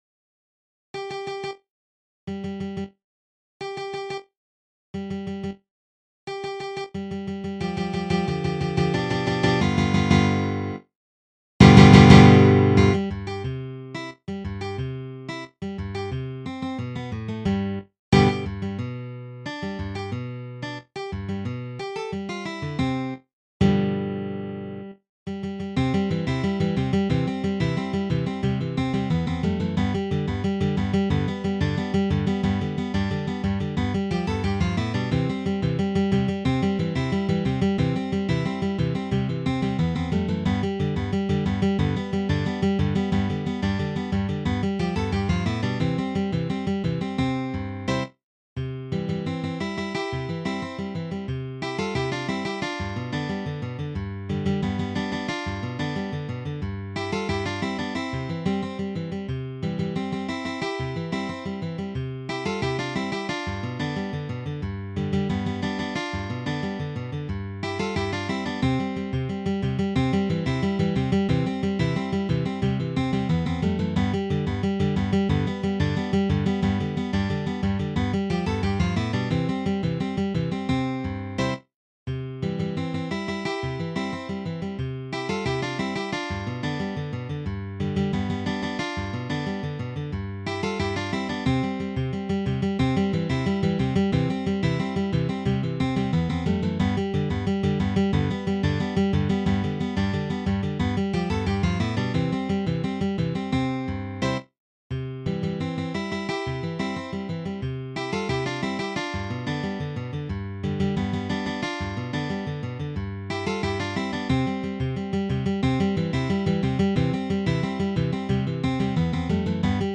for three guitars
The tempo may be played quite fast.